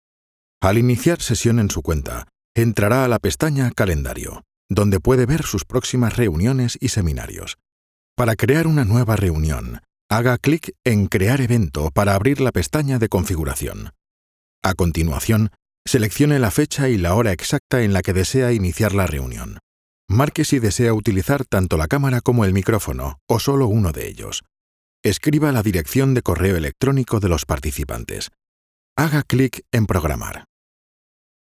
E-Learning
Mikrofon: Neumann TLM-103
Im mittleren Alter
Bass